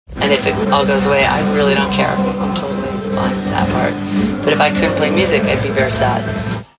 Interview on French TV, 1999